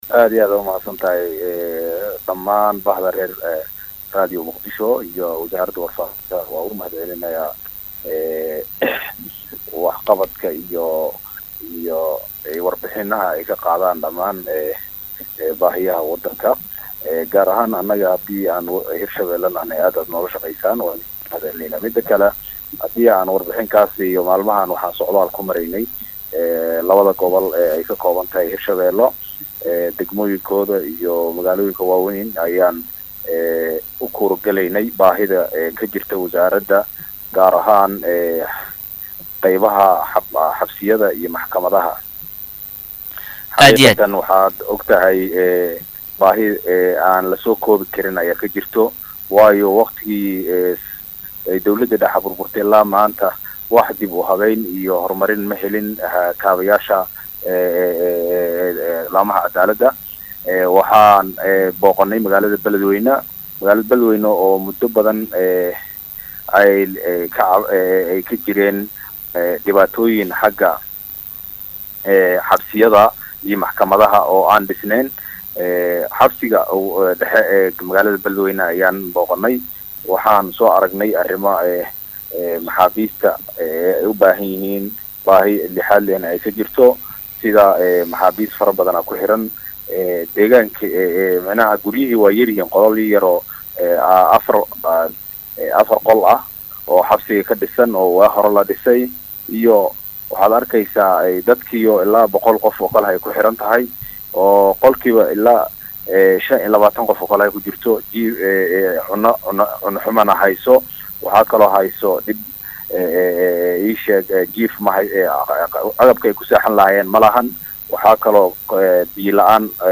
Wasiir Kheyraat oo la hadlay Radio Muqdisho ayaa sheegay in dhawaan uu socdaal shaqo ku tegay xabsiyada ku yaalla magaalooyinka Beladweyne iyo Jowhar, kaasi oo u jeedkiisu ahaa u kuurgelidda xaaladaha ay ku suganyihiin maxaabiista halkasi ku xiran, waxaana uu intaa ku daray in xabsiyada aysan helin wax dib u dayactir ah, loona baahanyahay in iminka ay ku sameeyaan dib u habeyn balaaran.